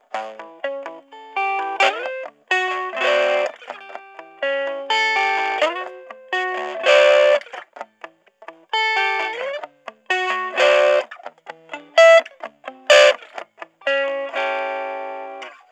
Before we get started, let me say that yes, the amp was recorded using an extension cable.
1983 Guild X175
Neck Cleanish
The main thing I took away from this recording session is that it’s very difficult to get a clean sound out of this amp unless you turn the volume down on the guitar, though that was also very guitar-dependent.
Guild-TweedyBird-X175-Neck-Cleanish.wav